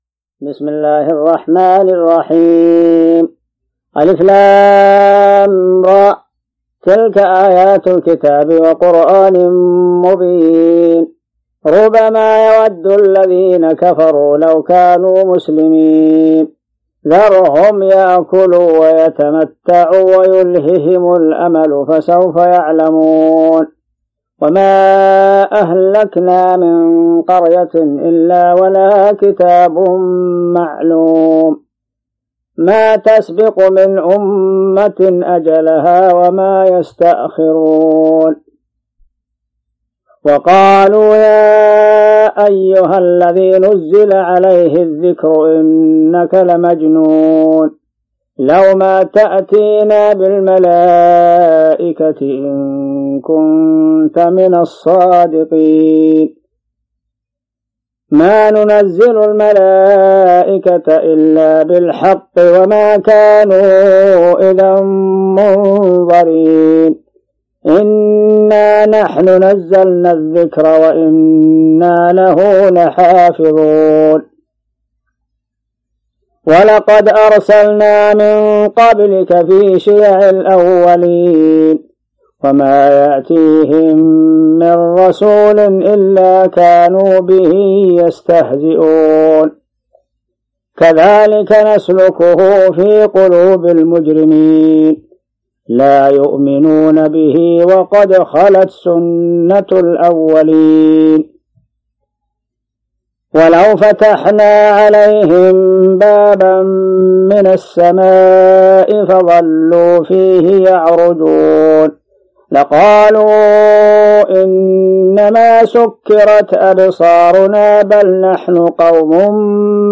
تلاوات
28 جوان 2017 م رواية : حفص عن عاصم الحجم:5.4M المدة